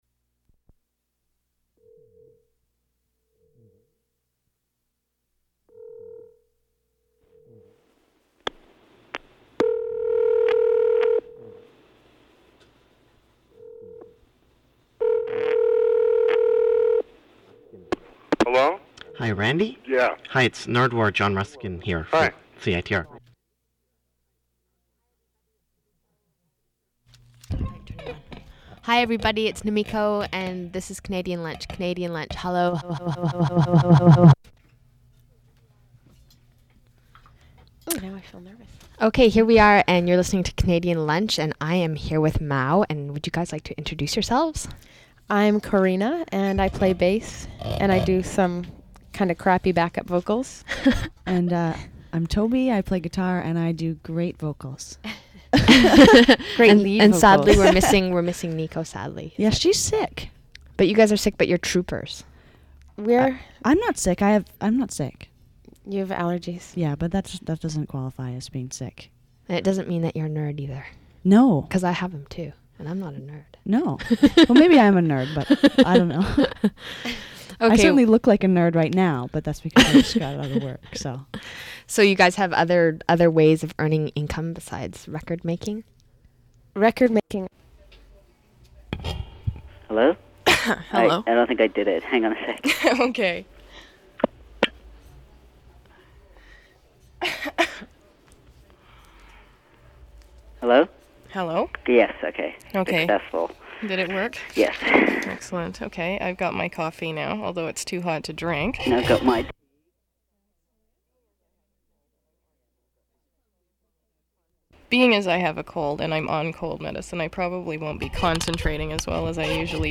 phone interview